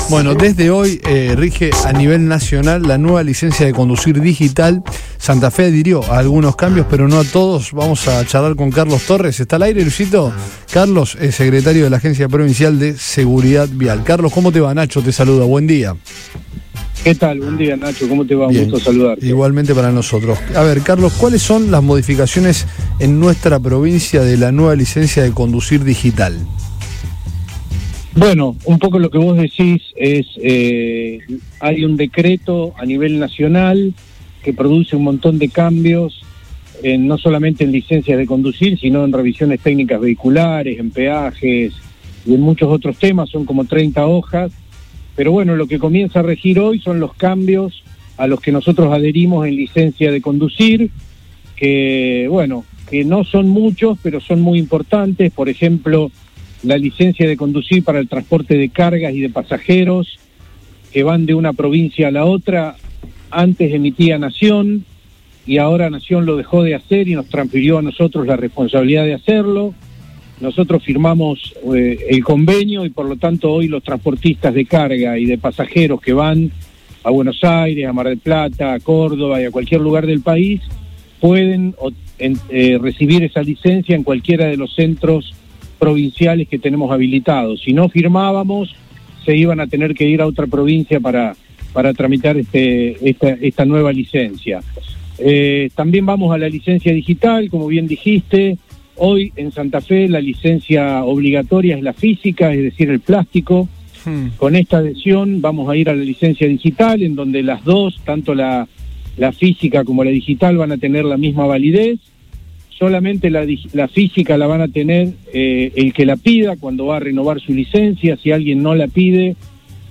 El secretario de la Agencia Provincial de Seguridad Vial (APSV), Carlos Torres, junto a funcionarios del organismo, brindó precisiones sobre cómo se implementarán las modificaciones en el territorio provincial.